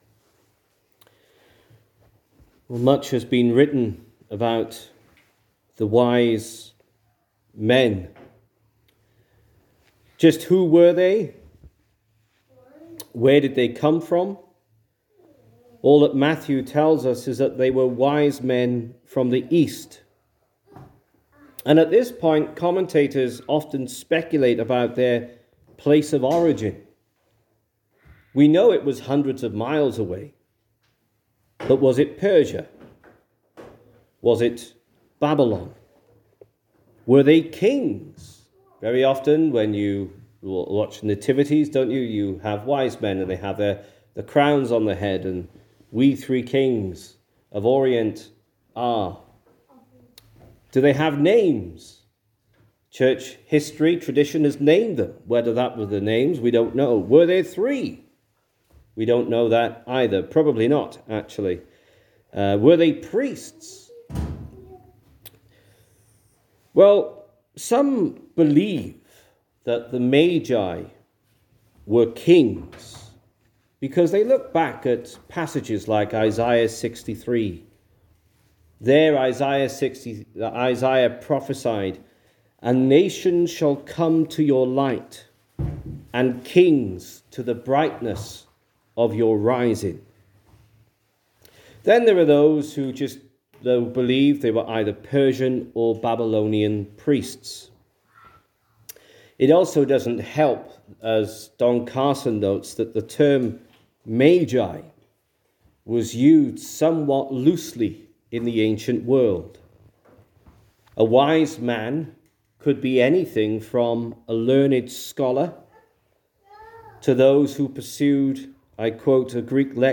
sermon-wisemen.mp3